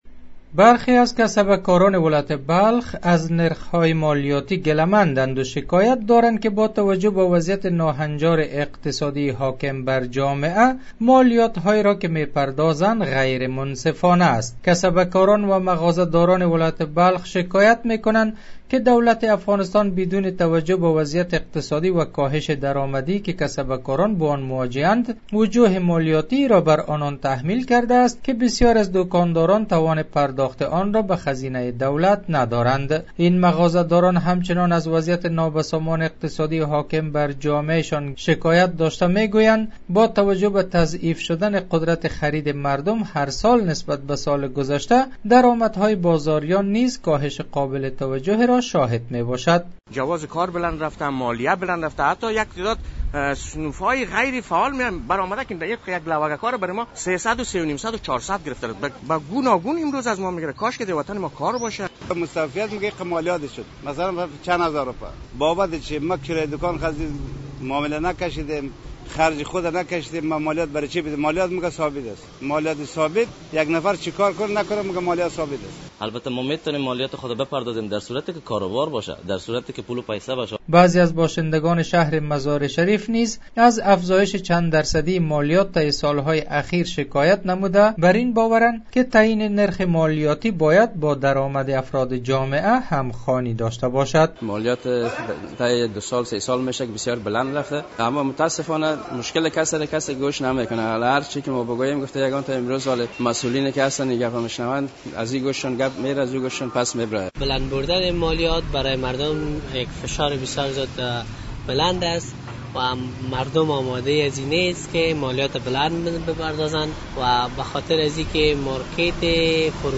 گزارش : نارضایتی کسبه های ولایت بلخ از نرخ مالیات